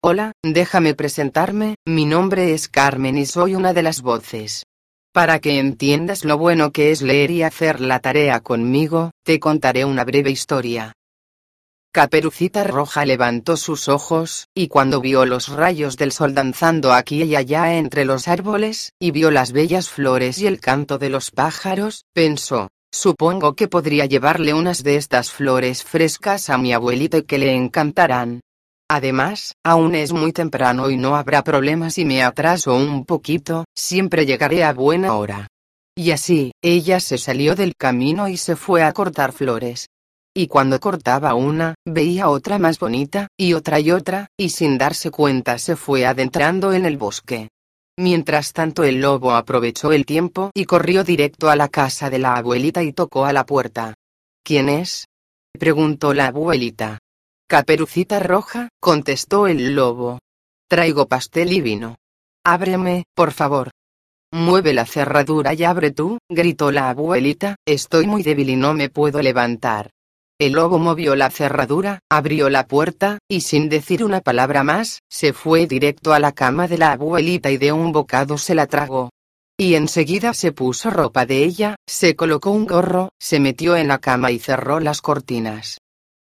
Per questo motivo, la voce risulta estremamente gradevole, chiara e fluente e priva del timbro metallico tipico delle voci artificiali.
Voce spagnola Carmen
Carmen-normale.mp3